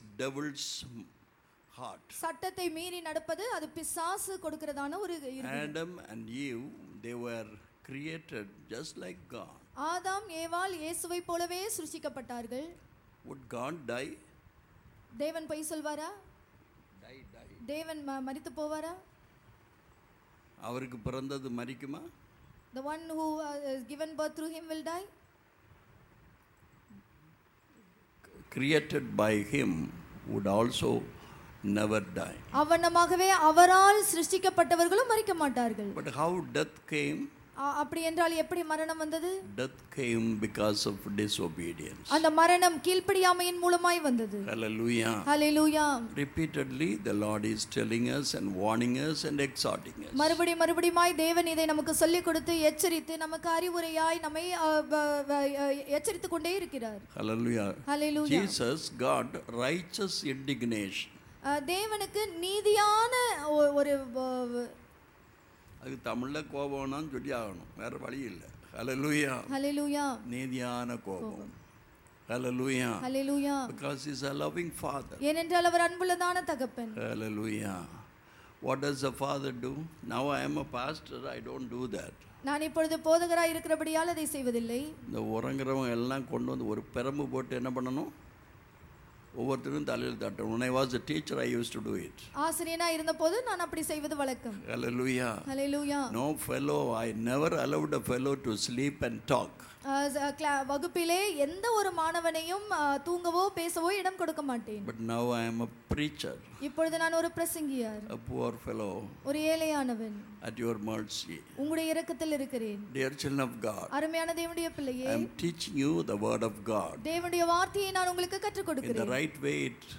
6 August 2023 Sunday Morning Service – Christ King Faith Mission
Sunday Morning Service_Worship Sunday Morning Service_Message Download: Worship | Message Luke 19:10 For the Son of man is come to seek and to save that which was lost.